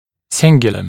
[‘sɪŋgjuːləm][‘сингйу:лэм]небный бугорок резца (мн.ч. cingula)